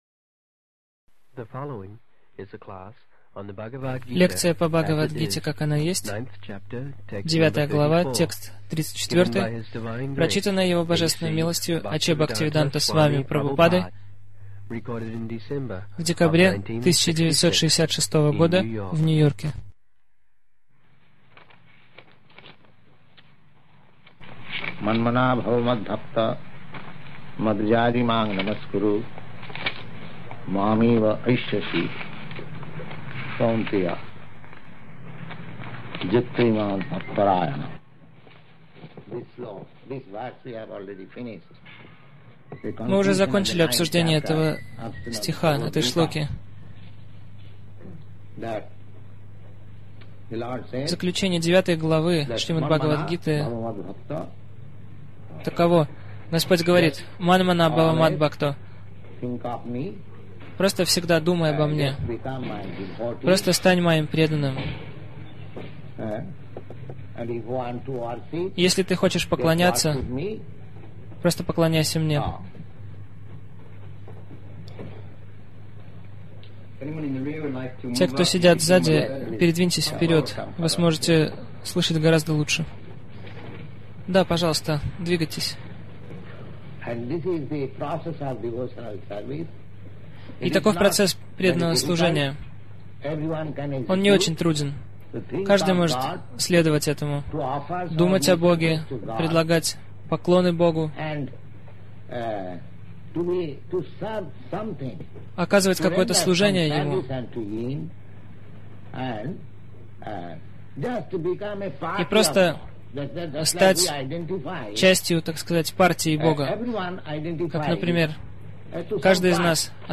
Милость Прабхупады Аудиолекции и книги 24.12.1966 Бхагавад Гита | Нью-Йорк БГ 09.34 Загрузка...